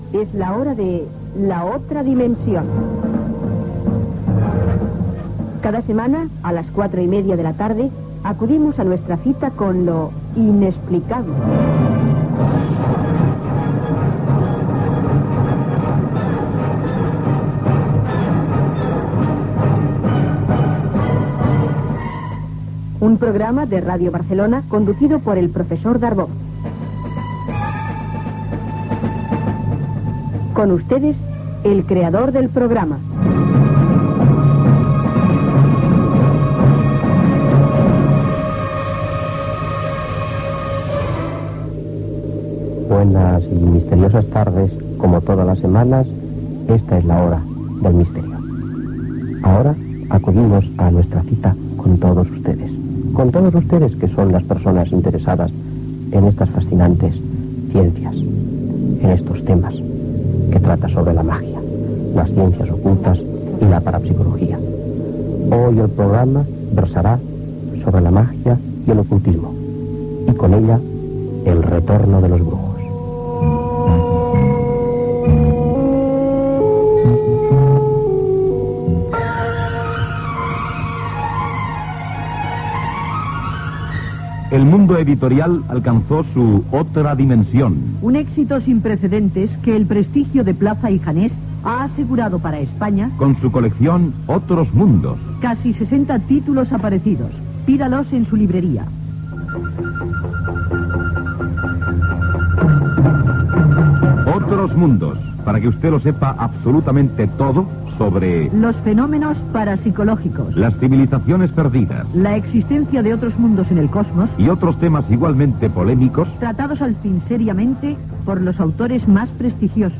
Careta del programa, presentació, tema, publicitat, resum de la tercera III Convenció Catalana de Simbologia i Psicociències, a Núria.
Divulgació